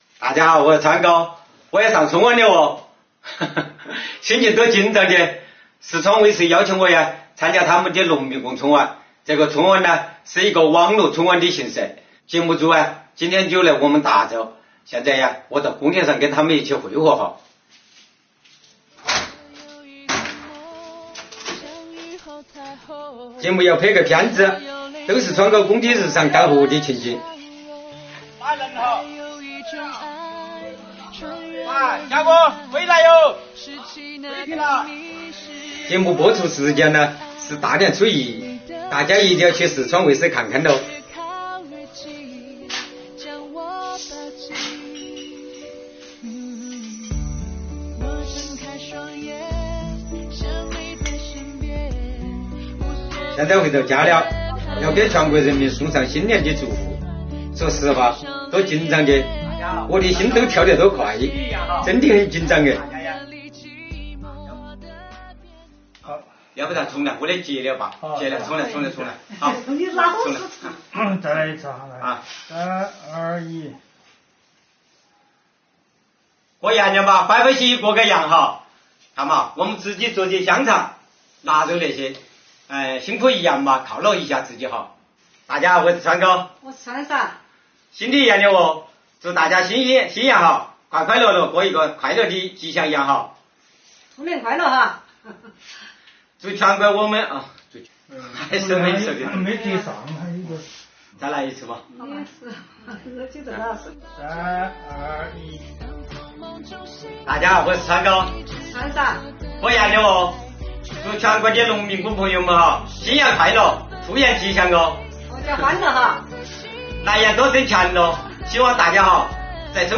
一口川音，两张带着汗水的笑脸